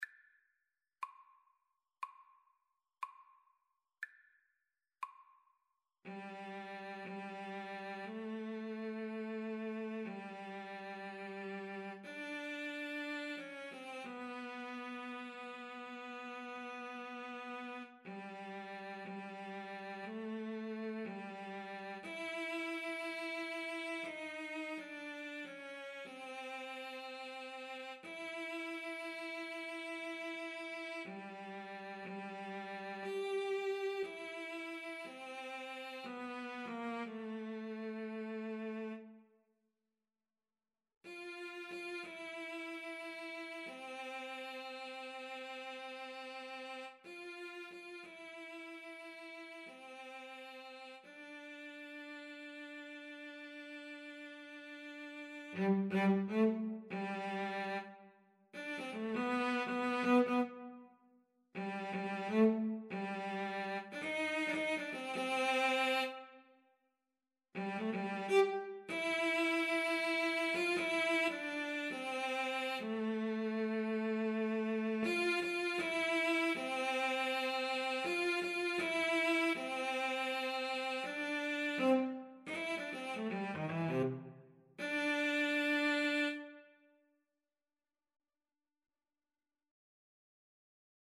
Freely, bluesy and smoochy =60
4/4 (View more 4/4 Music)
Jazz (View more Jazz Cello Duet Music)